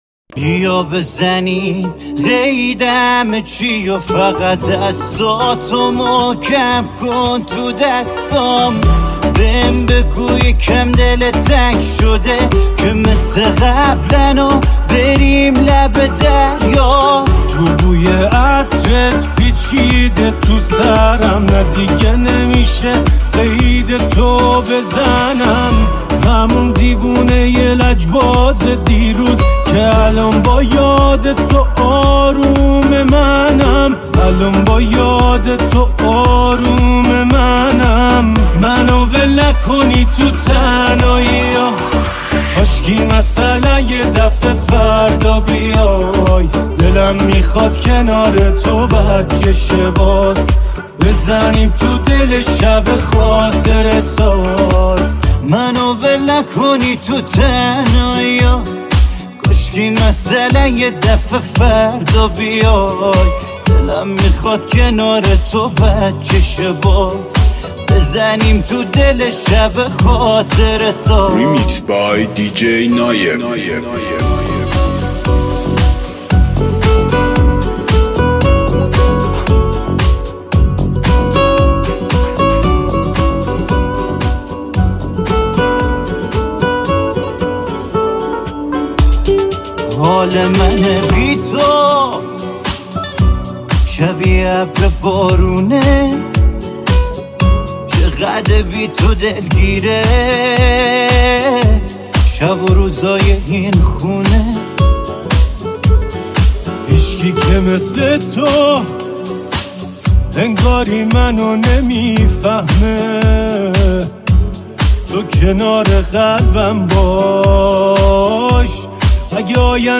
بیا بزنیم قید همه چیو فقط دستاتو ریمیکس تند بیس دار سیستمی